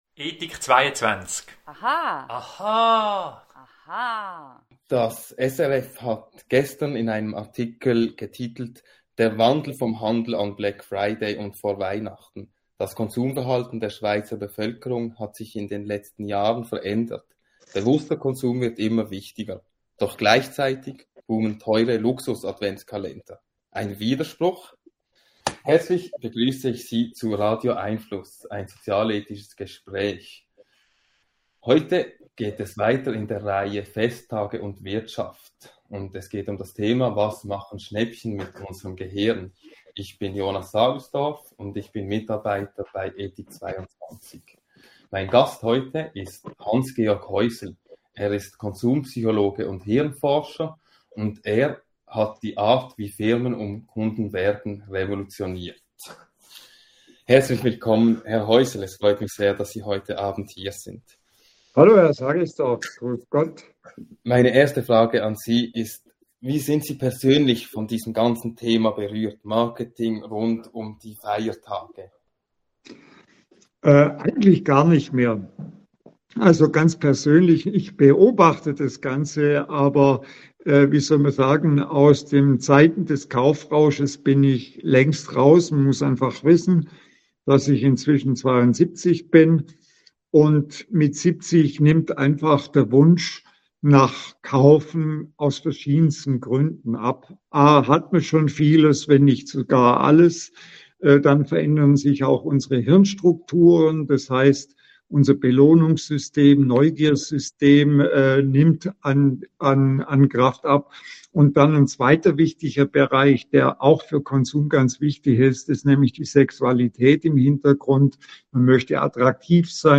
Bleiben Sie über die kommenden Radio🎙einFluss Audio-Gespräche informiert!